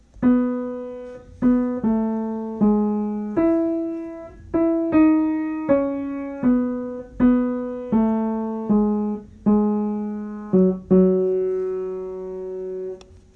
TENOR II: Hymn #220: Lord, I Would Follow Thee
Audition Key: E major* |
Starting Pitch: B | Sheet Music
tenor-2-hymn-220-e-major-m4a